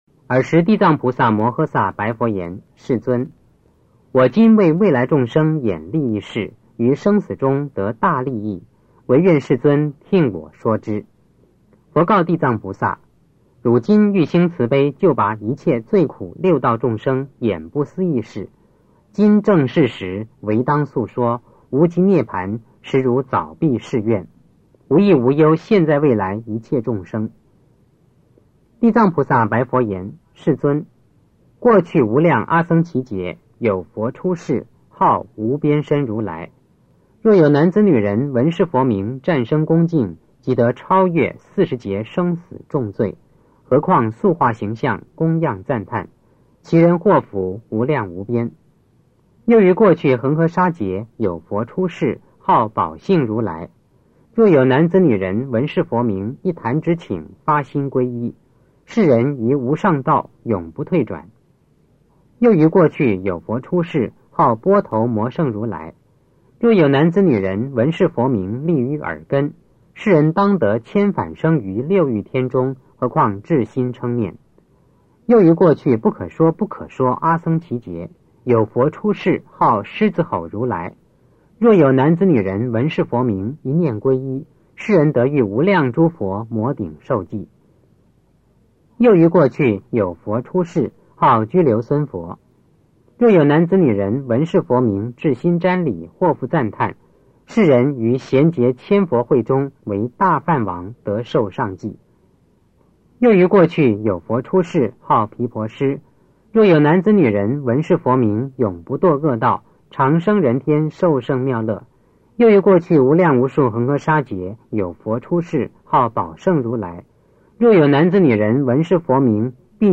地藏经(男声念诵）7